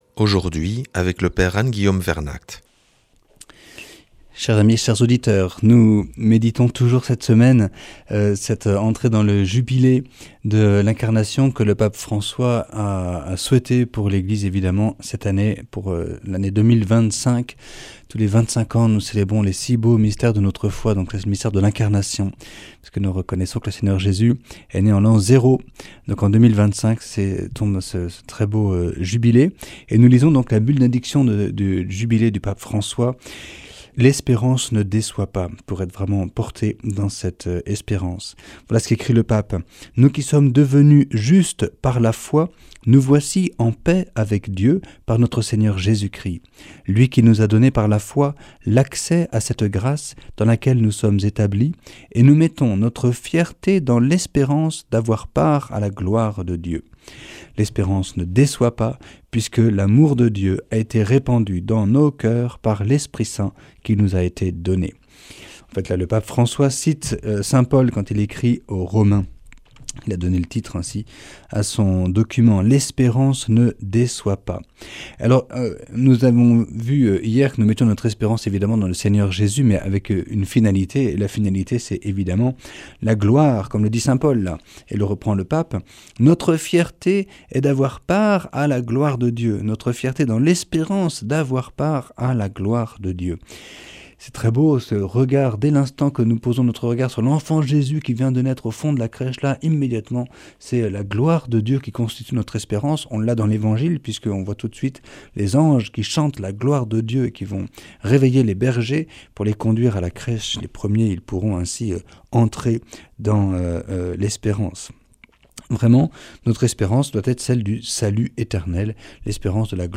une lecture de la Bulle d’indiction du Jubilé de l’Espérance du Pape François.